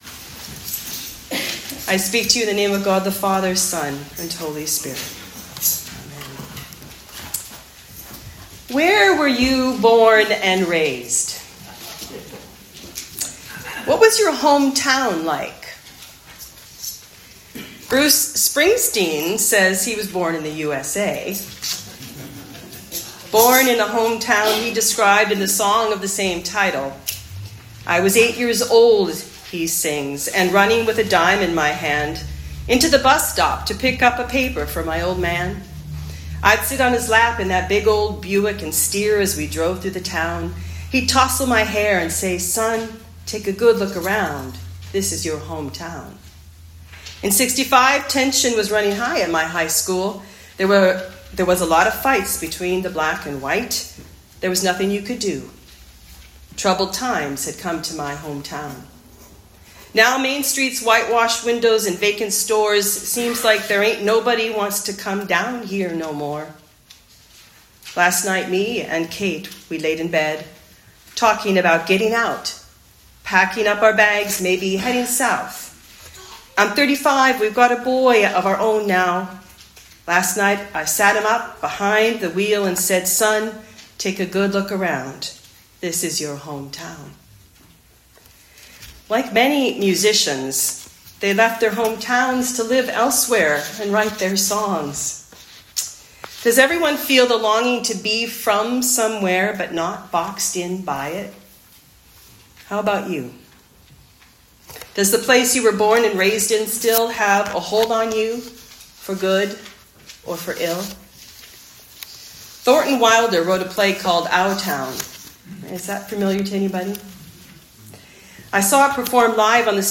Sermons | Holy Trinity North Saanich Anglican Church
Easter Talk